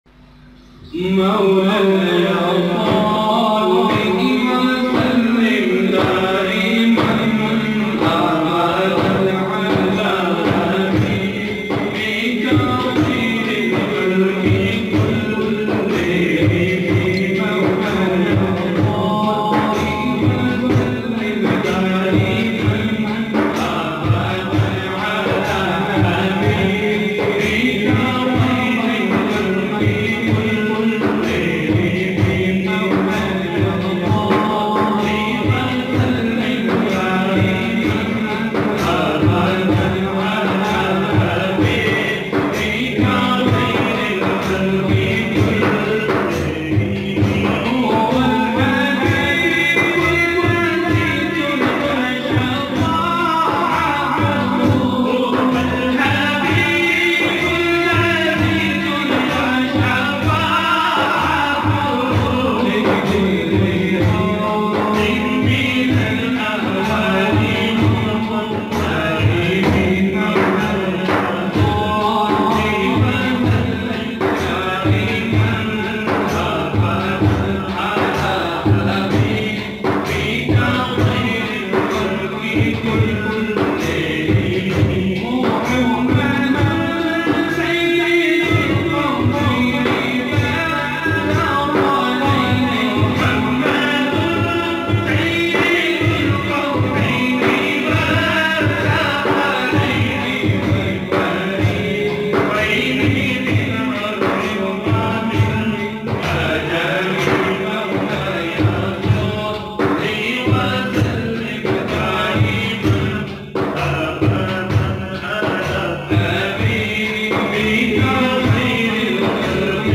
in beautfull voice